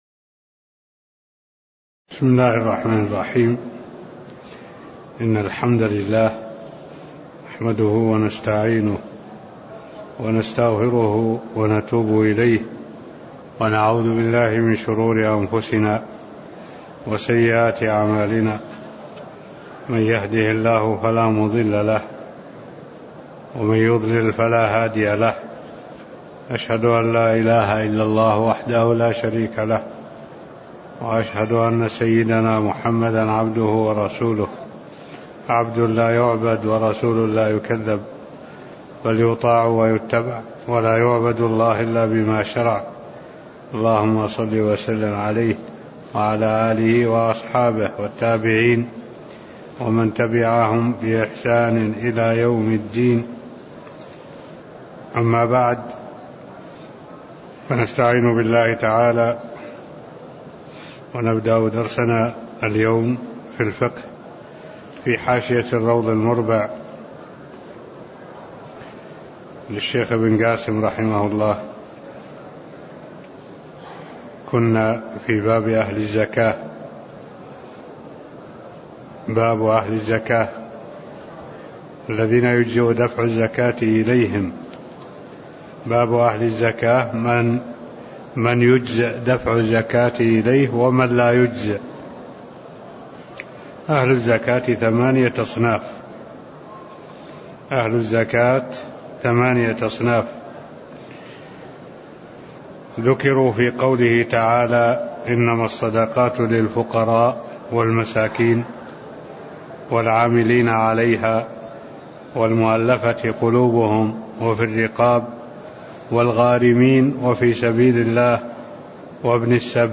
تاريخ النشر ٨ جمادى الأولى ١٤٢٧ هـ المكان: المسجد النبوي الشيخ: معالي الشيخ الدكتور صالح بن عبد الله العبود معالي الشيخ الدكتور صالح بن عبد الله العبود الصنف الثالث العاملون عليها (003)